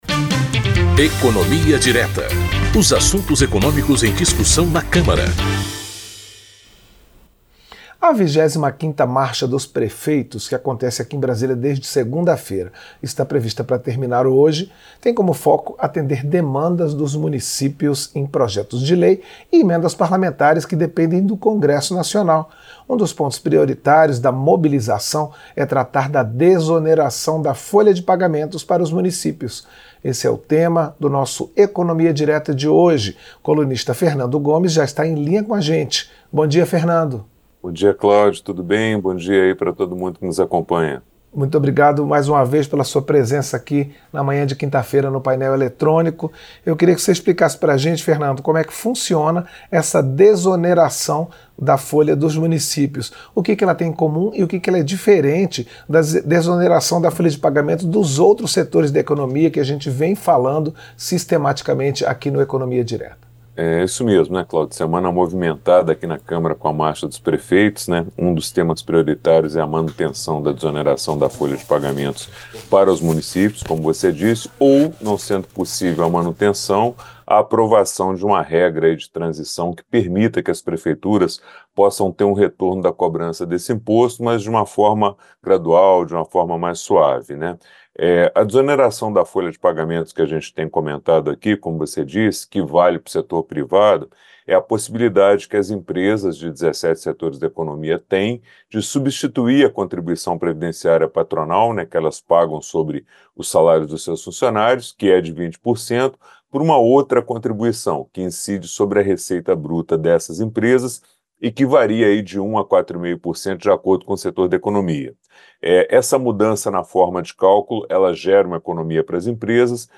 Comentário
Apresentação